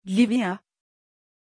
Pronunciation of Livia
pronunciation-livia-tr.mp3